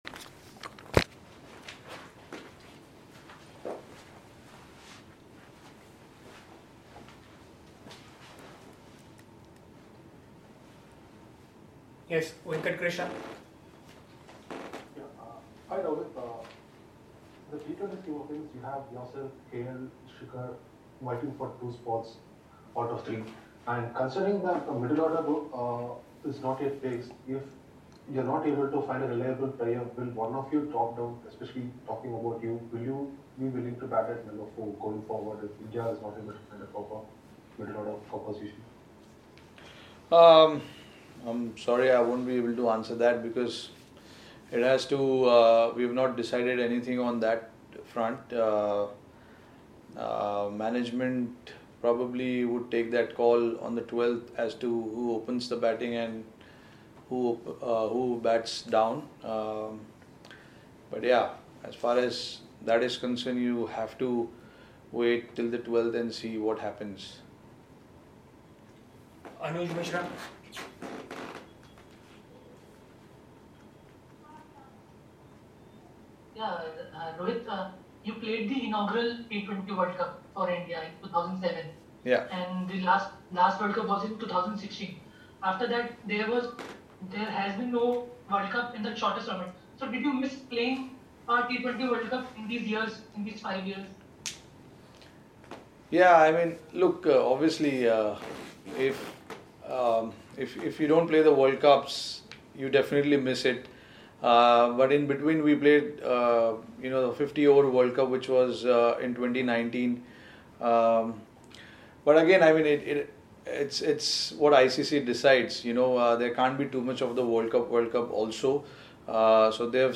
Rohit Sharma, Vice-Captain, Indian Cricket Team, addressed a virtual press conference ahead of the Paytm T20I series against England to be played at the Narendra Modi Stadium from Friday, 12th March.